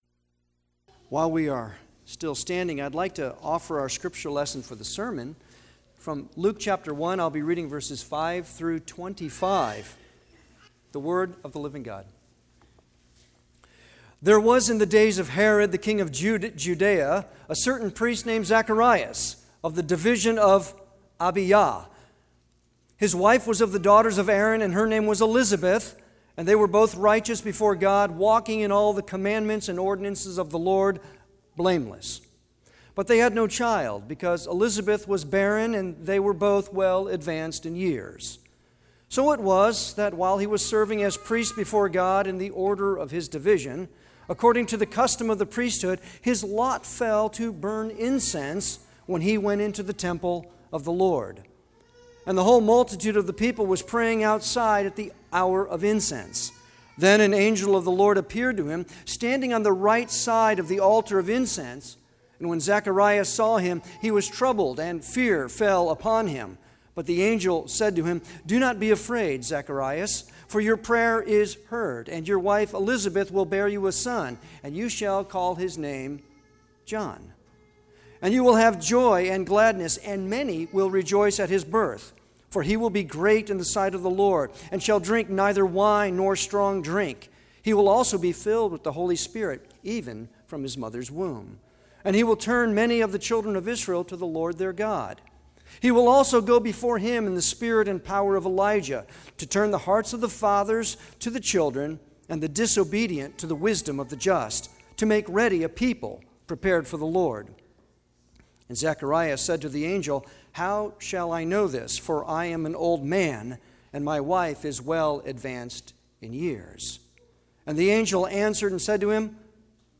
Advent Sermons
Service Type: Sunday worship